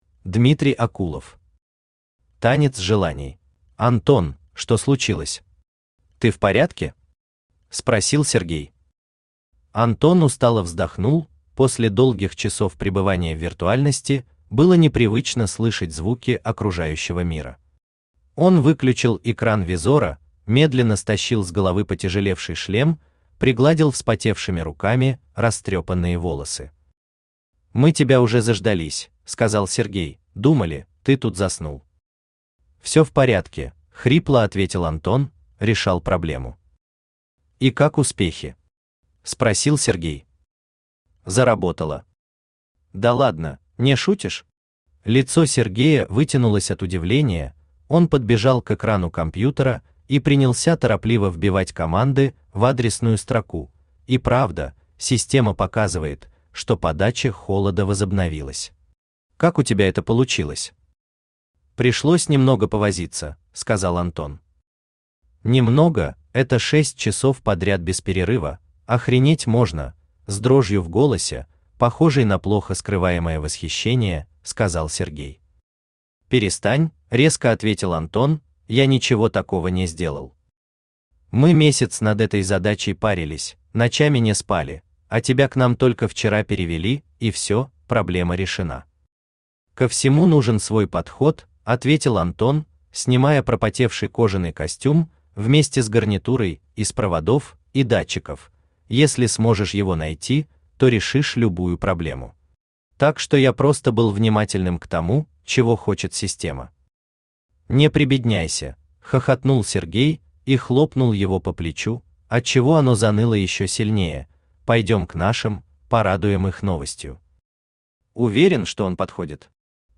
Aудиокнига Танец желаний Автор Дмитрий Эльёрович Акулов Читает аудиокнигу Авточтец ЛитРес.